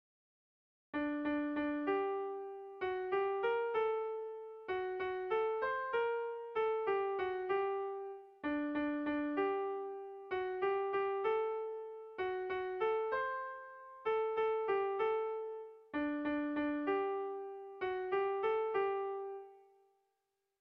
Lau puntuko berdina, 8 silabaz
ABAB